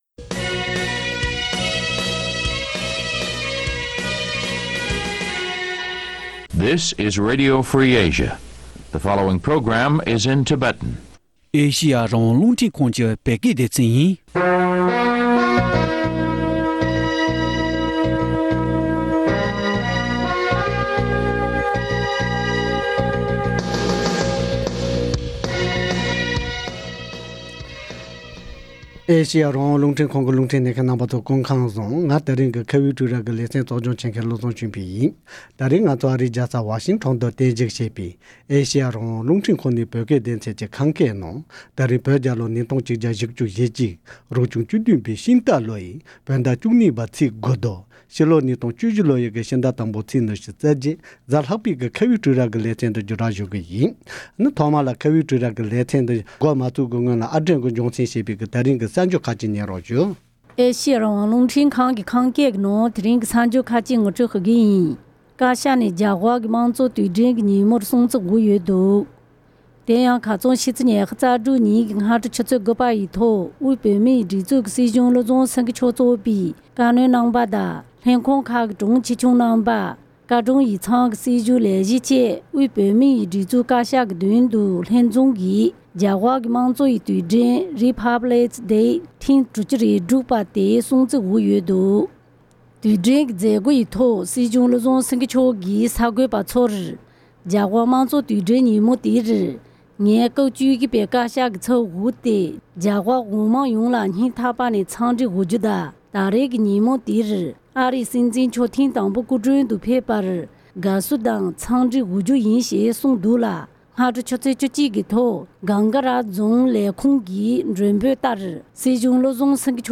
༄༅། །དེ་རིང་ང་ཚོའི་ཁ་བའི་གྲོས་རྭ་ཞེས་པའི་ལེ་ཚན་ནང་ཕ་མས་ཕྲུག་གུ་ལ་བྱམས་སྐྱོང་གི་ཐོག་ནས་གསོ་སྐྱོང་ཇི་ལྟར་བྱེད་དགོས་མིན་དང་ཕྲུ་གུས་ཕ་མ་ལ་བརྩེ་སེམས་དང་བཀྲིན་རྗེས་དྲན་ཇི་ལྟར་ཞུ་དགོས་མིན་སོགས་ཀྱི་སྐོར་ལ་འབྲེལ་ཡོད་ཁག་ཅིག་དང་གླེང་མོལ་ཞུས་པར་གསན་རོགས་གནང་།།